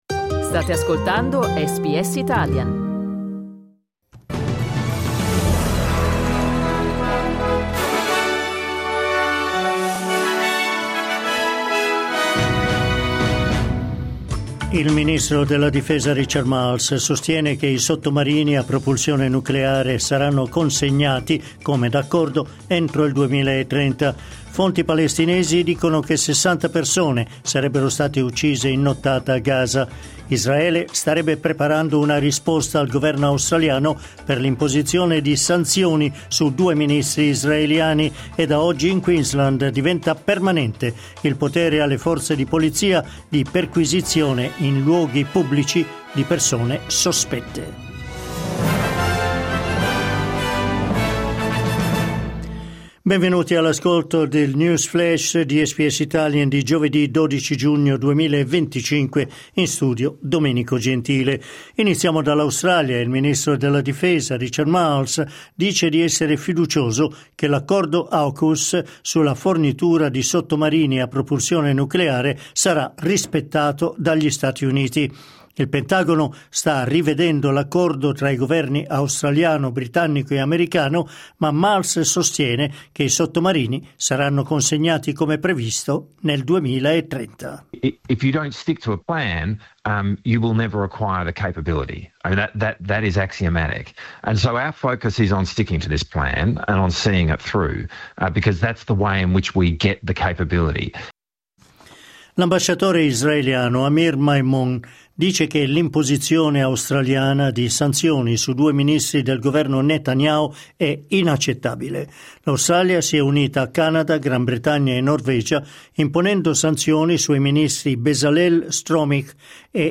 News flash giovedì 12 giugno 2025